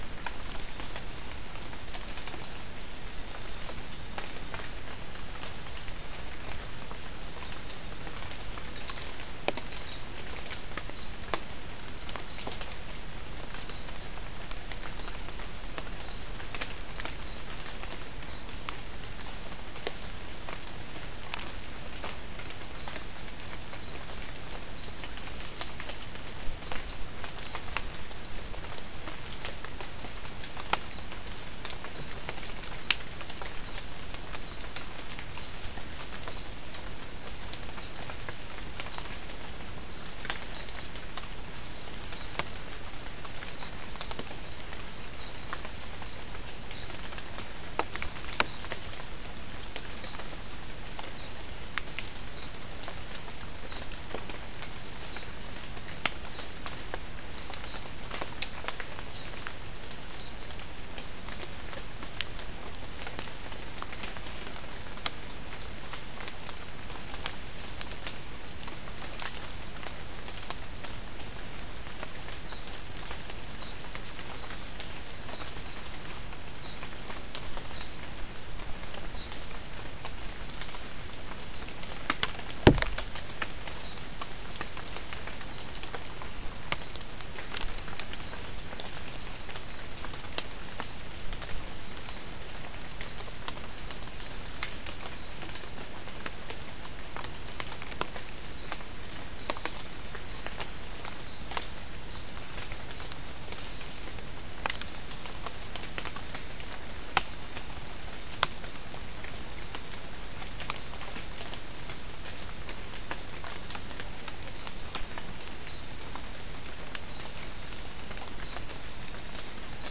Non-specimen recording: Soundscape Recording Location: South America: Guyana: Kabocalli: 2
Recorder: SM3